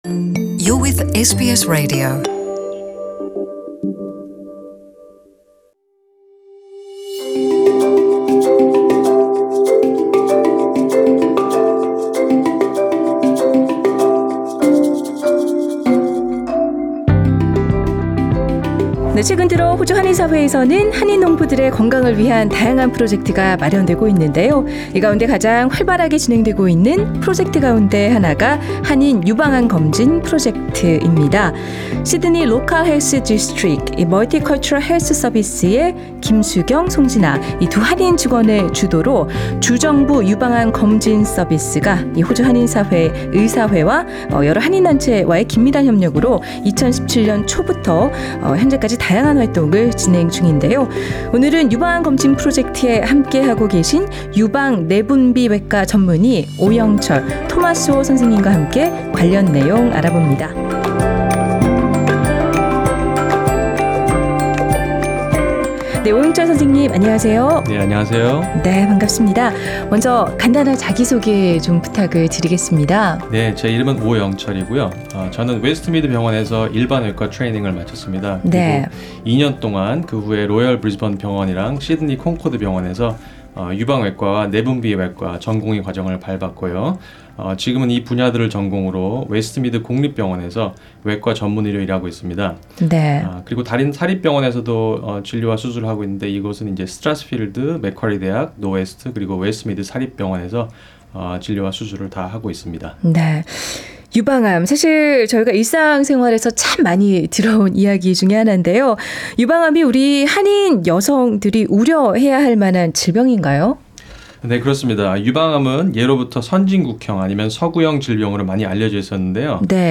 The full interview